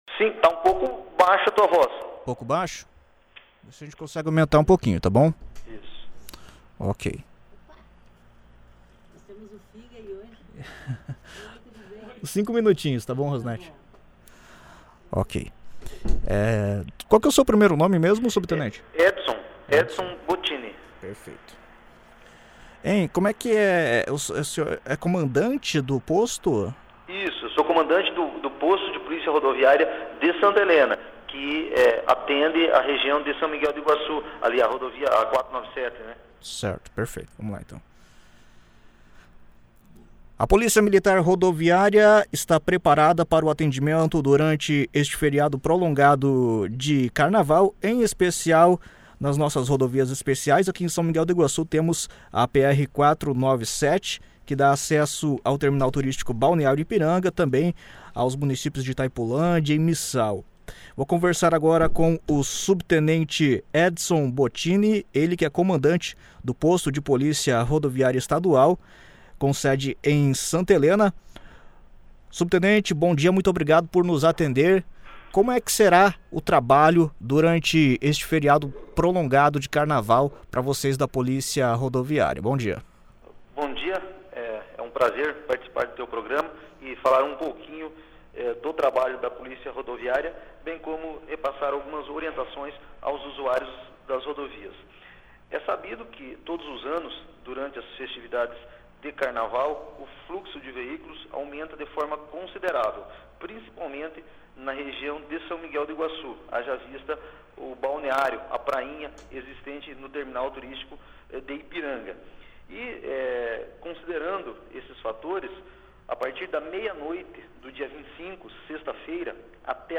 Em entrevista à Rádio Jornal São Miguel